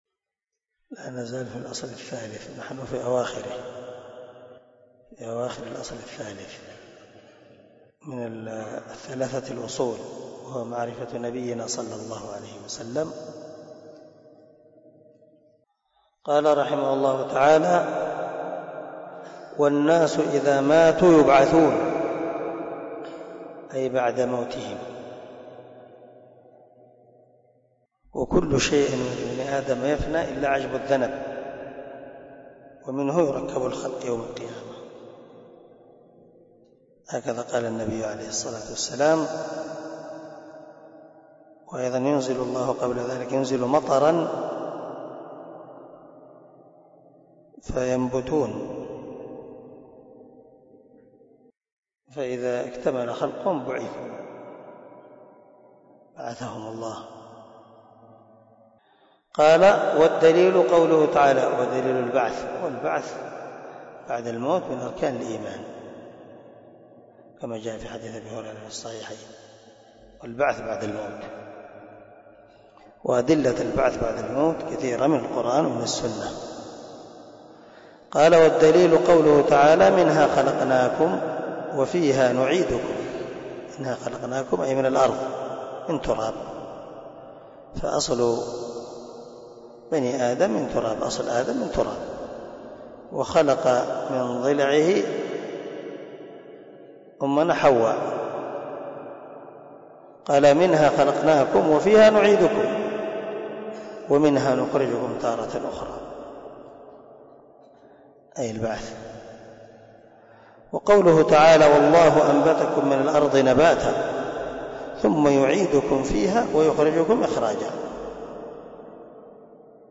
🔊 الدرس 37 من شرح الأصول الثلاثة